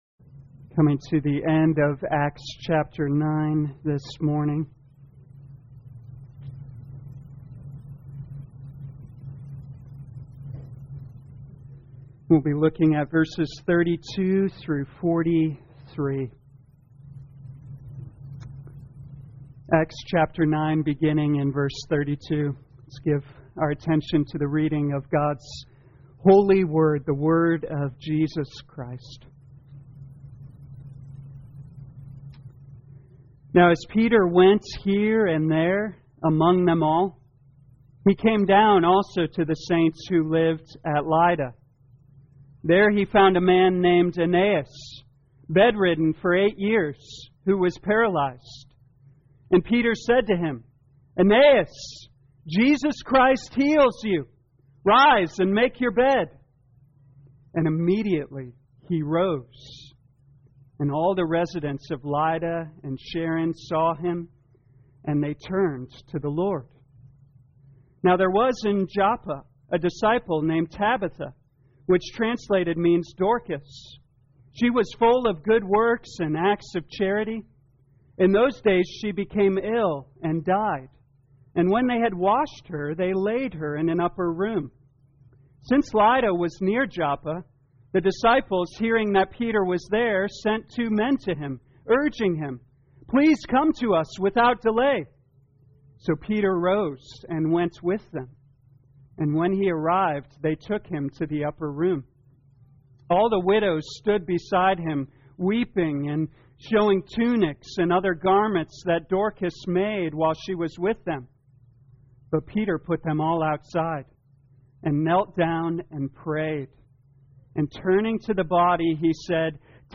2021 Acts Morning Service Download